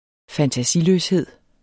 Udtale [ fantaˈsiløsˌheðˀ ]